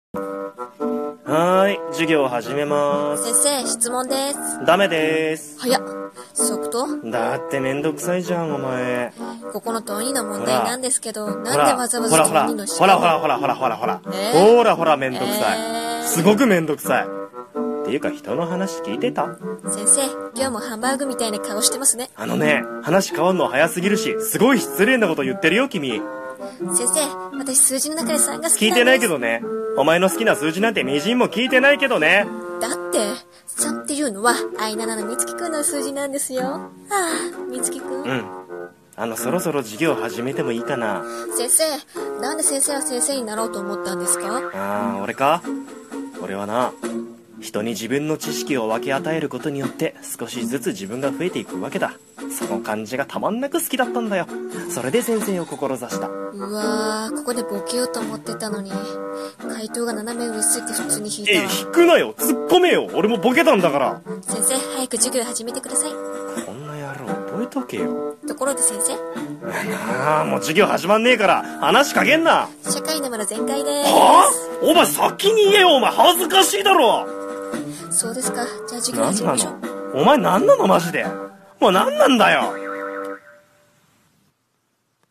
【声劇台本】